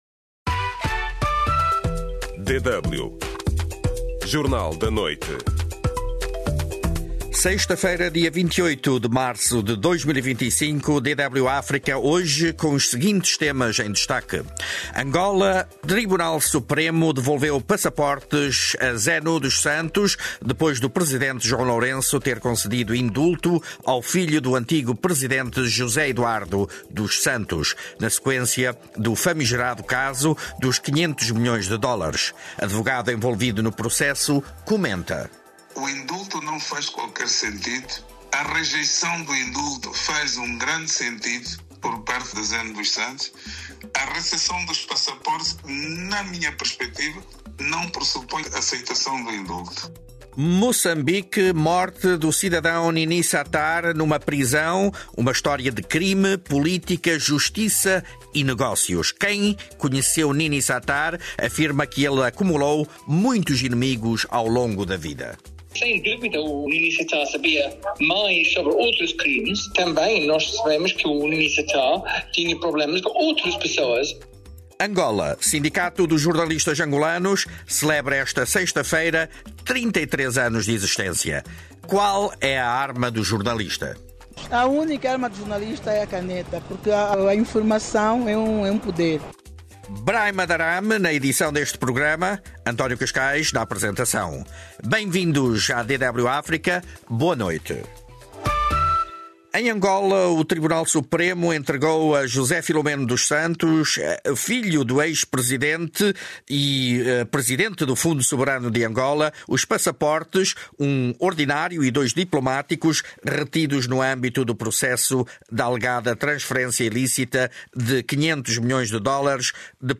MS+ Portugiesisch Noite 19:30 UTC - MP3-Stereo. 25 de Abril de 2024 - Jornal da Manhã.